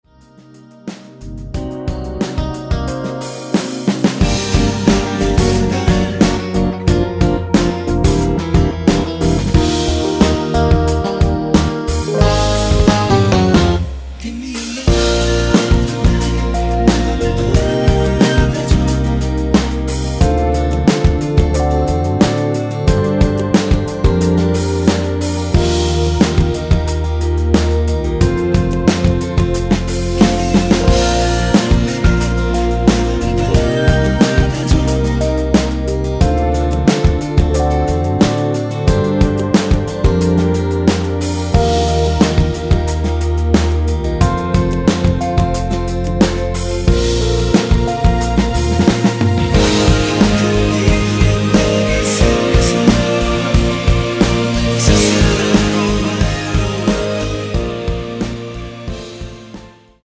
원키에서(-1)내린 코러스 포함된 MR입니다.
Bb
앞부분30초, 뒷부분30초씩 편집해서 올려 드리고 있습니다.